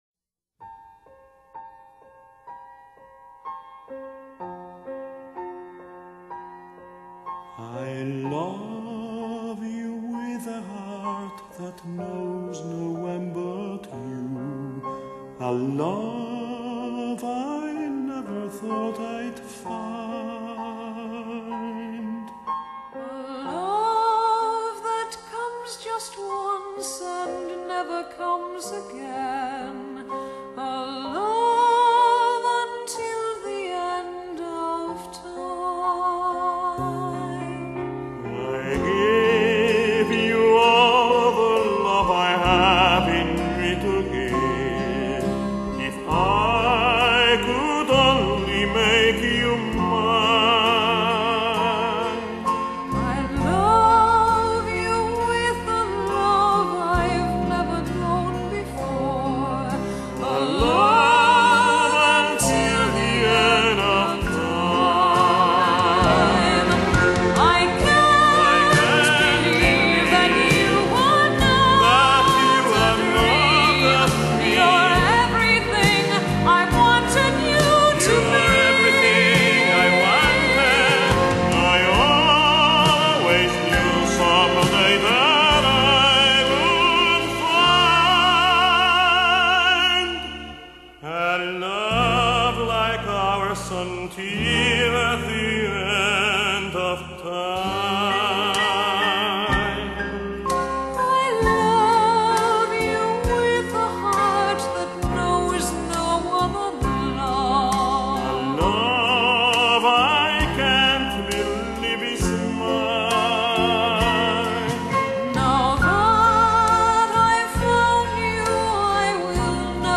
本輯屬於情歌對唱